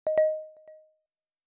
notification-sound.wav